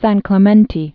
(săn klə-mĕntē)